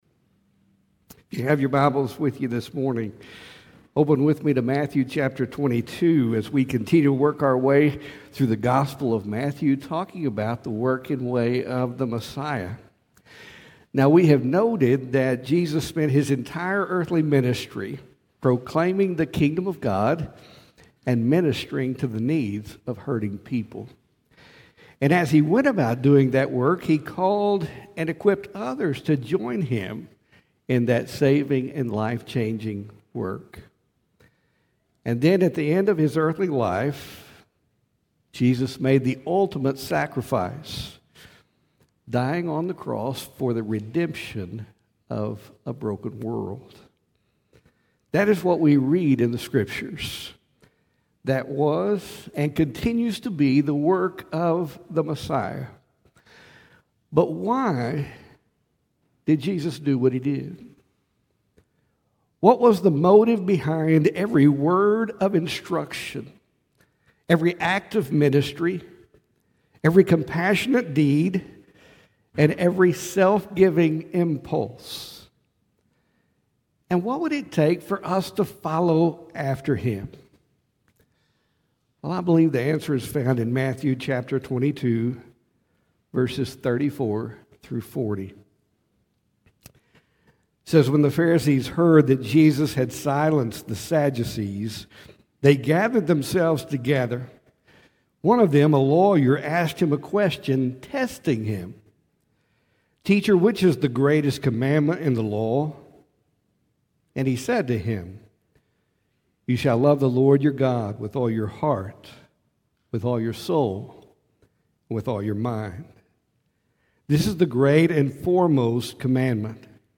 Sermons | First Baptist Church Brownwood